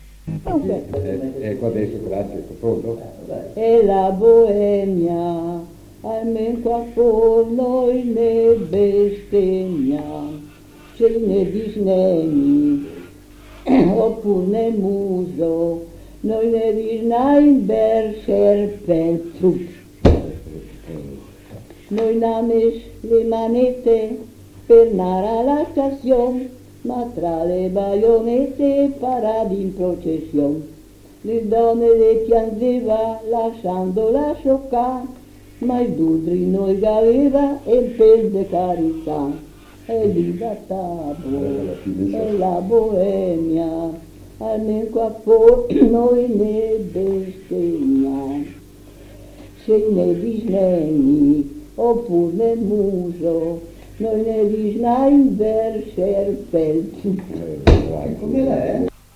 Registrazioni di canti popolari